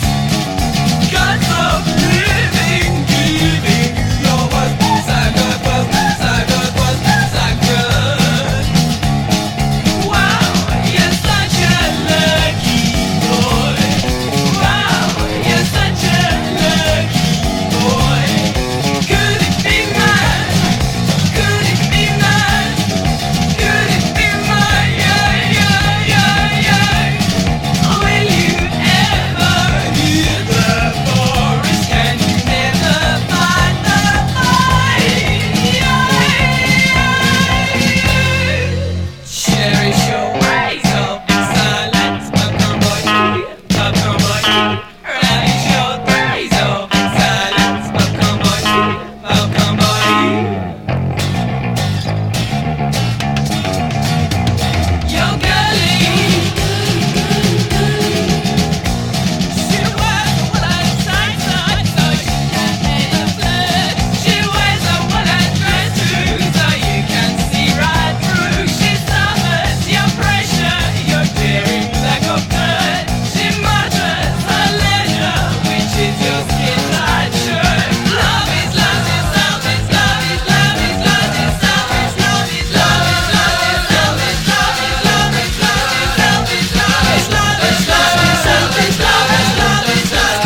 NEW WAVE FUNK (UK)
ポストパンク〜ダブ〜アヴァンギャルドを横断し